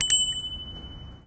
Ring
bell bicing bike bikestation campus-upf ding ring ringing sound effect free sound royalty free Sound Effects